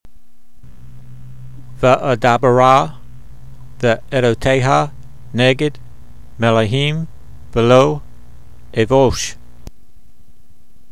Transliteration: